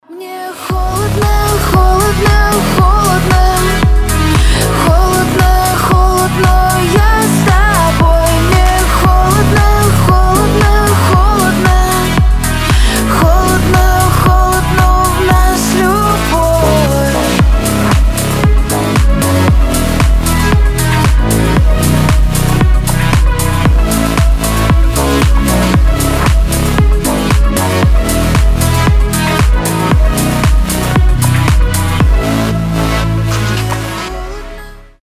• Качество: 320, Stereo
deep house